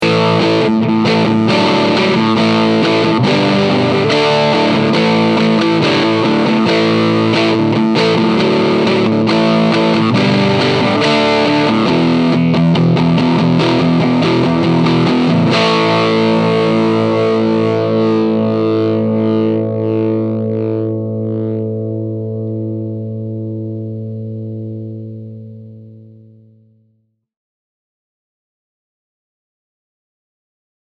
Talk about tonal complexity!
Both Pickups
Rock Rhythm
I used a Sennheiser e609 instrument mic, and recorded directly into GarageBand with no volume leveling.
both_rock.mp3